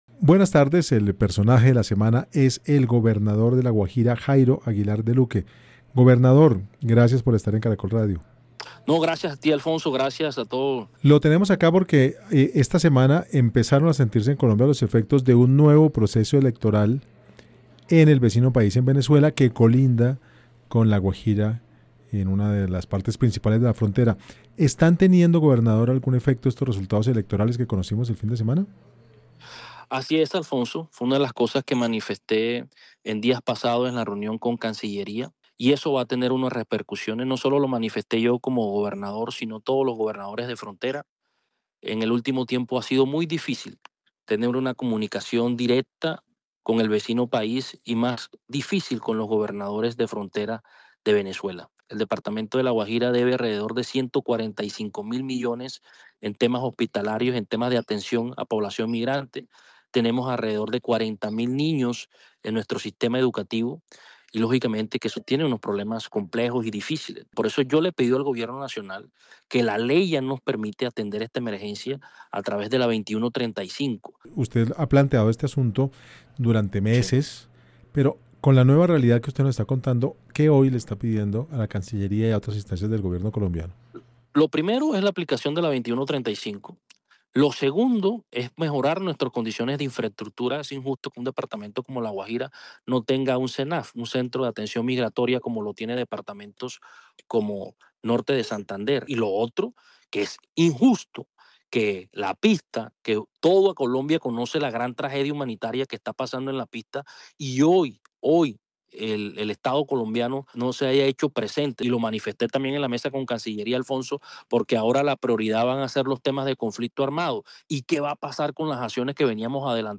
Jairo Aguilar Deluque, gobernador de La Guajira y personaje de la semana en Caracol Radio, advirtió que: “El departamento enfrenta una deuda cercana a los 145 mil millones de pesos en temas relacionados con servicios hospitalarios y atención a la población migrante”.